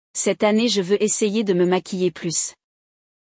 Hoje, vamos ouvir duas amigas conversando sobre maquiagem.